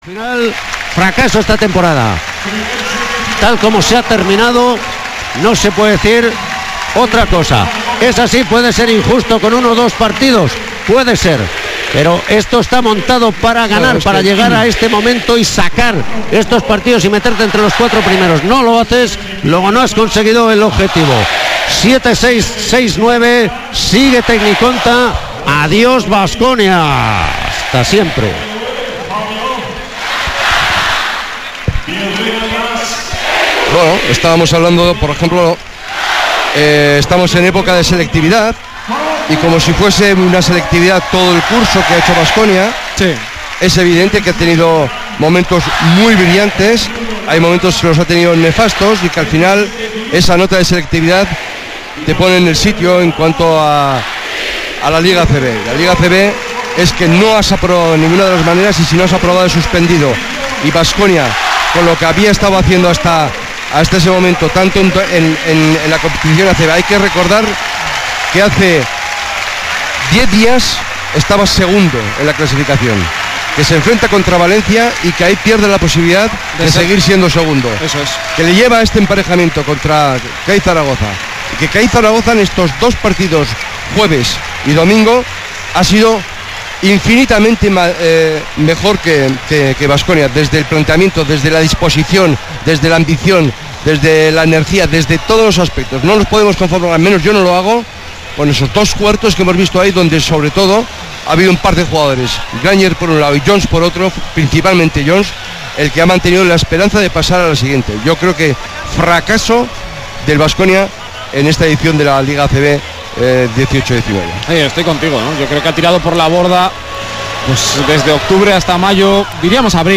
Post partido,rueda de prensa
retransmisión Radio Vitoria